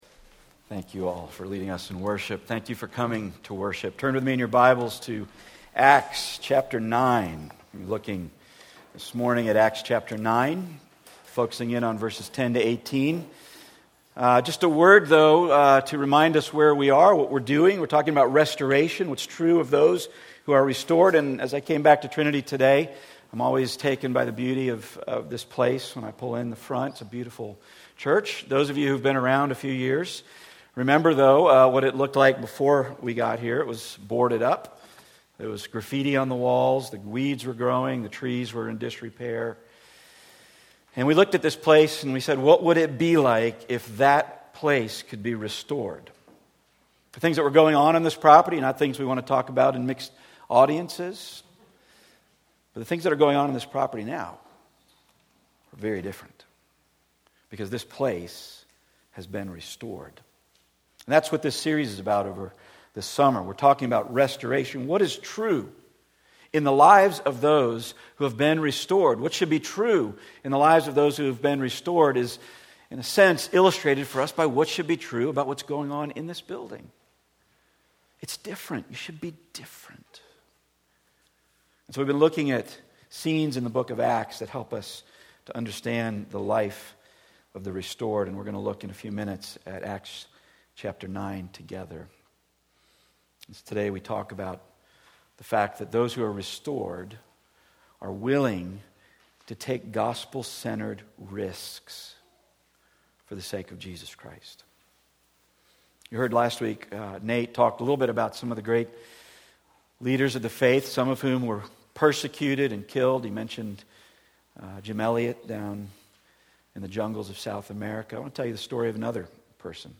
Service Type: Weekly Sunday